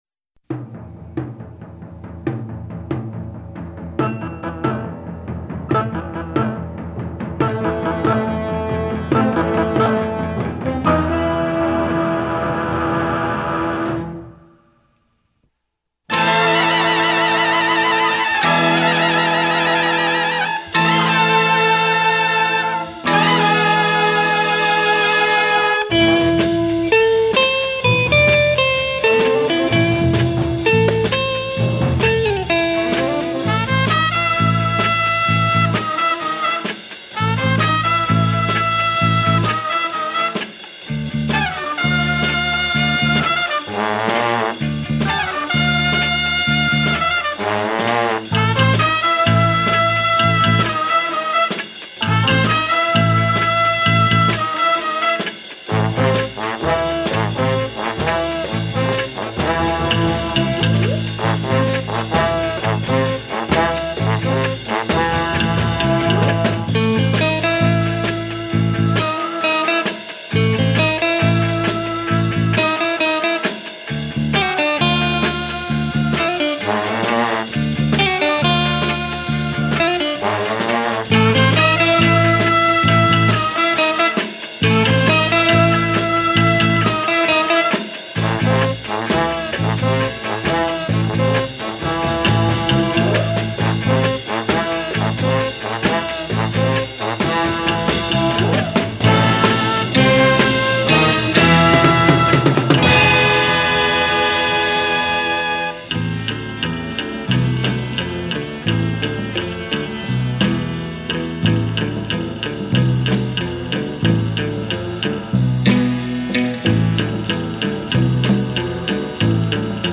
les décharges ténébreusement funky